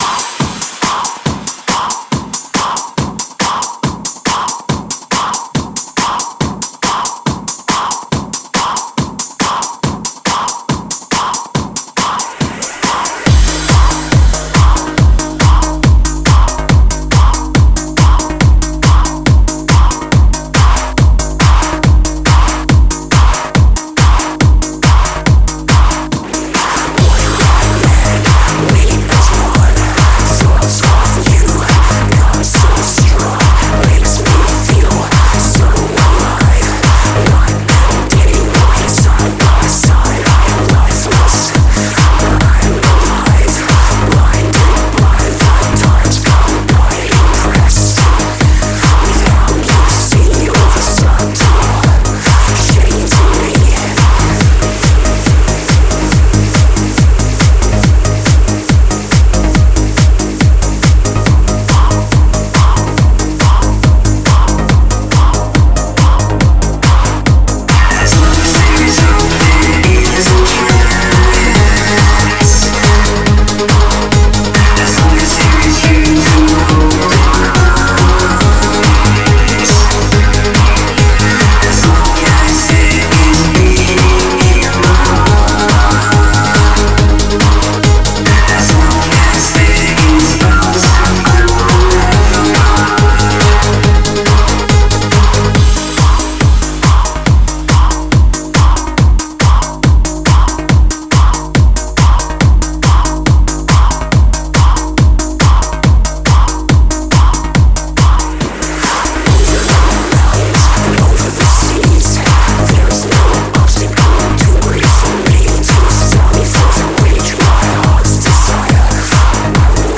The newest in industrial, gothic, synthpop, post-punk, and shoegaze music, requests, the silly question, and more.